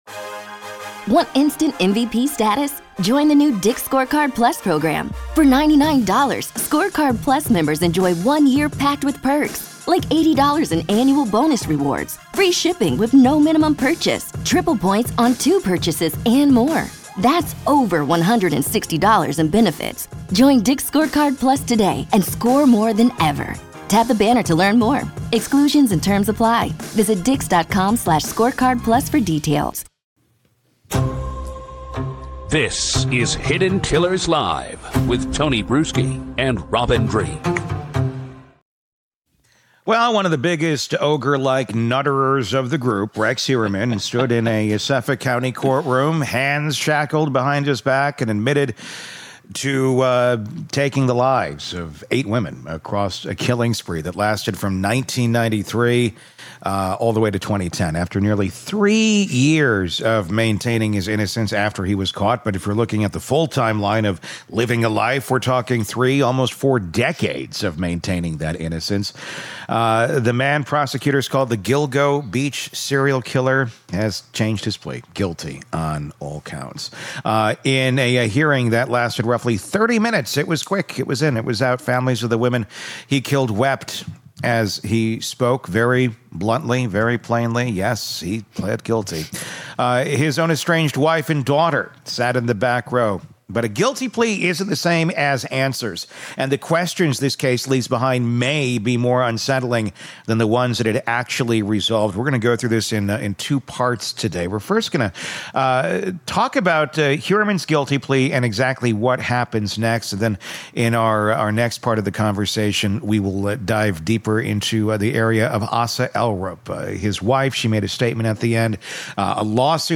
Rex Heuermann’s Plea and the Duggar Pattern: A Panel Breakdown